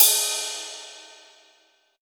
• Ambient Ride One Shot E Key 04.wav
Royality free ride cymbal single hit tuned to the E note. Loudest frequency: 9279Hz
ambient-ride-one-shot-e-key-04-0fZ.wav